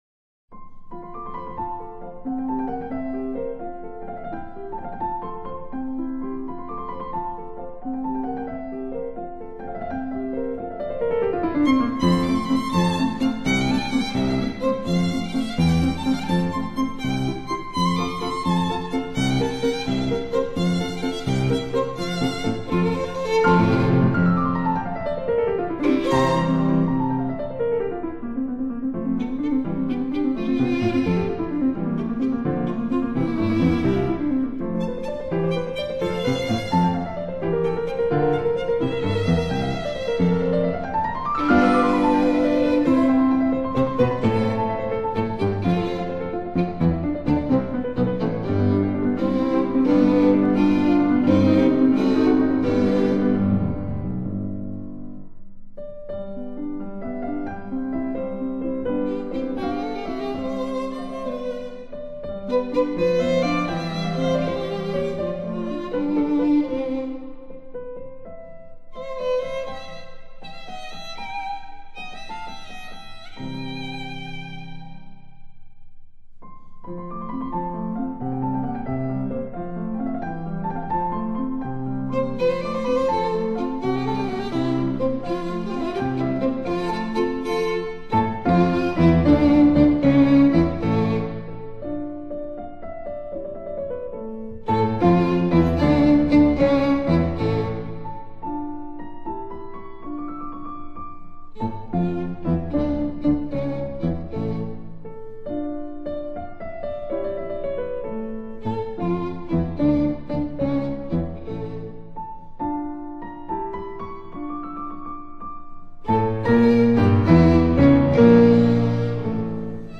鋼琴與提琴